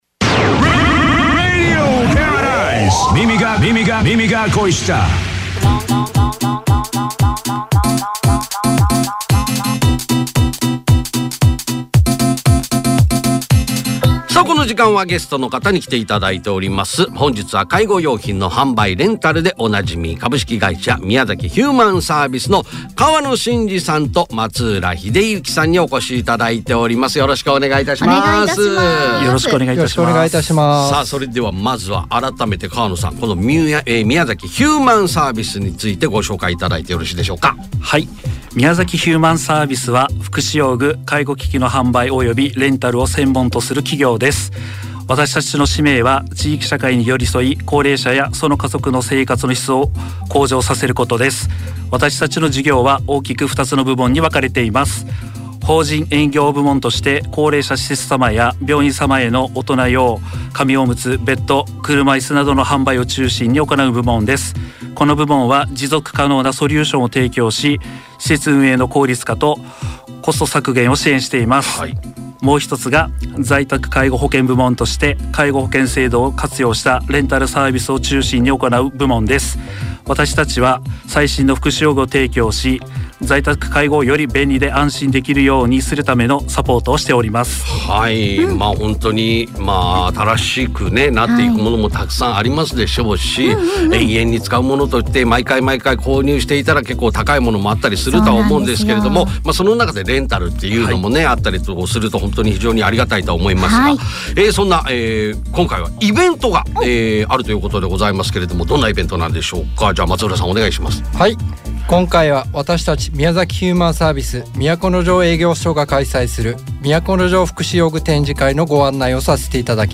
（若干かみましたが・・・(>_<)）